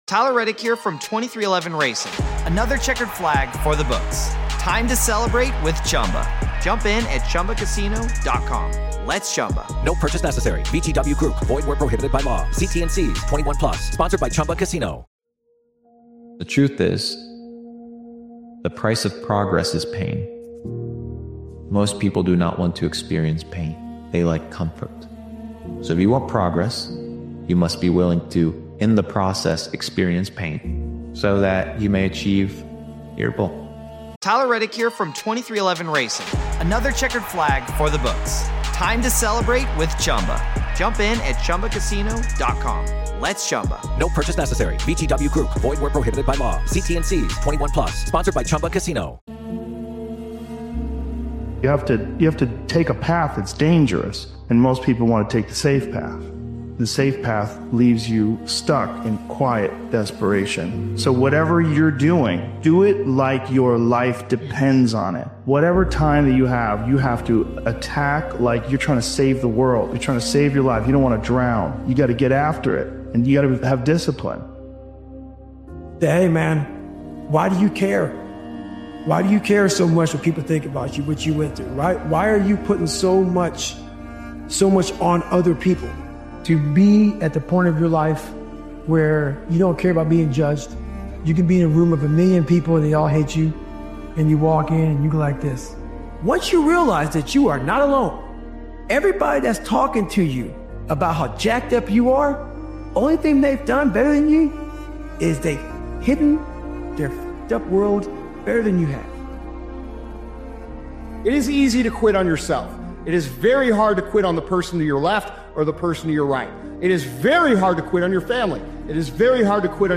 Refuse to be average, and decide now to be exceptional. One of the Best Motivational Speeches Ever